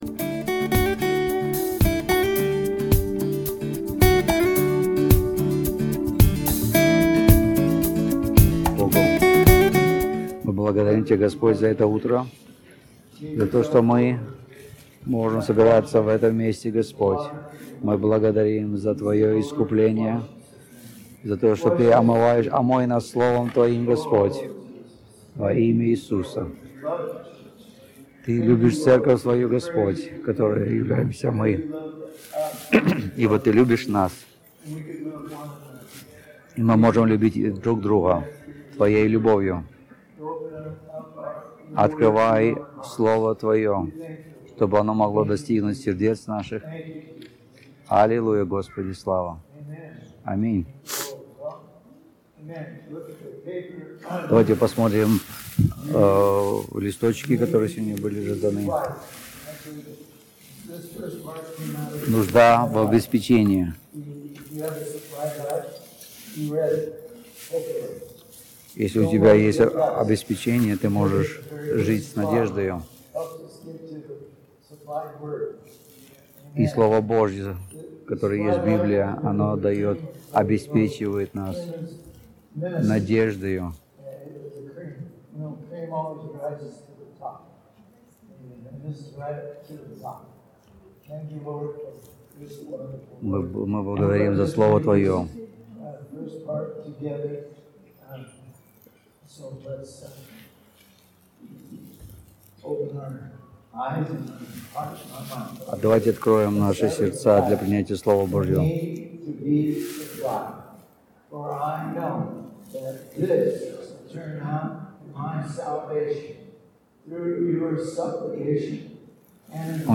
Русские проповеди